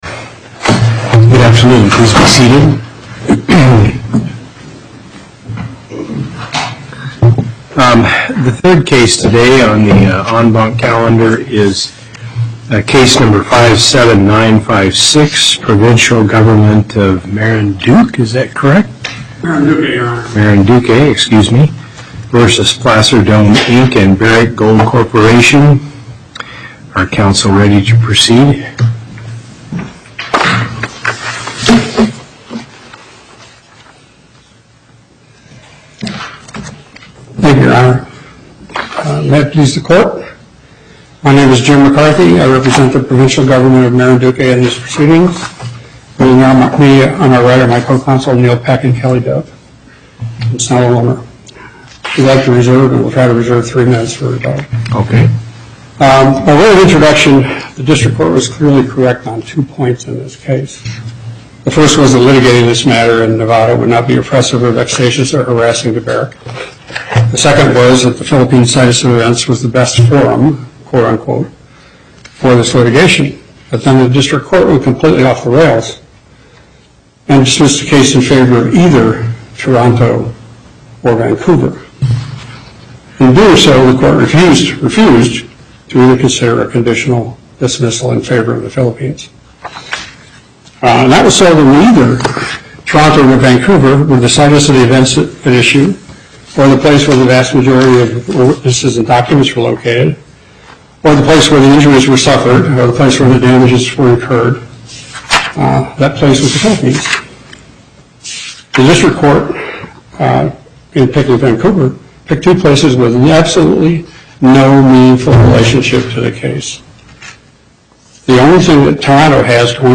Location: Las Vegas Before the En Banc Court, Chief Justice Hardesty Presiding